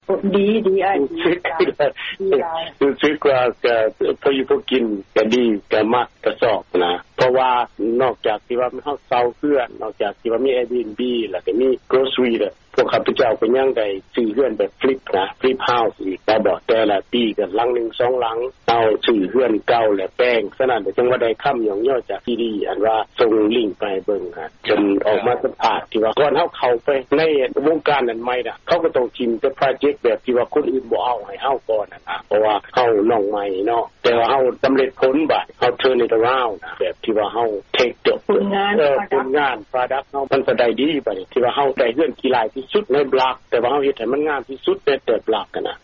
ທີ່ທ່ານຫາກໍໄດ້ຮັບຟັງຜ່ານໄປນັ້ນ ແມ່ນການໂອ້ລົມກັບລາວອາເມຣິກັນຄອບຄົວນຶ່ງ ໃນເຂດເມືອງໂທເລໂດ ລັດໂອຮາຍໂອ.